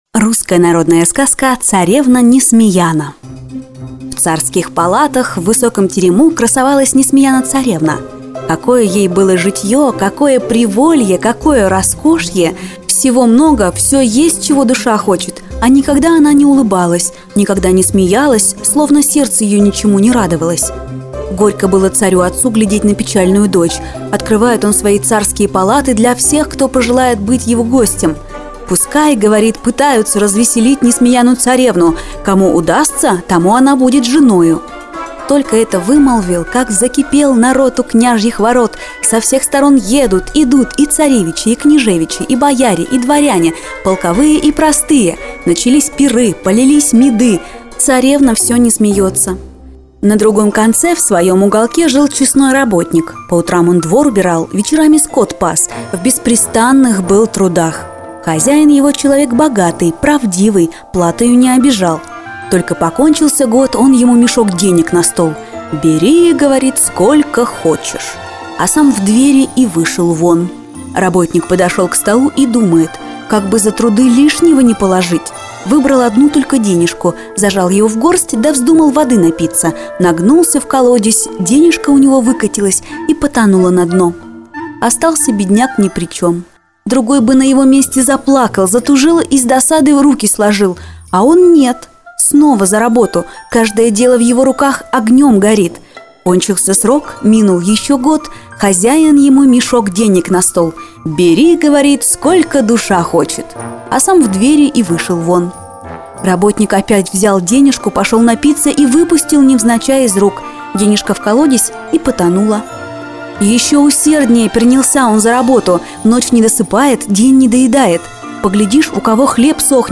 Русская народная сказка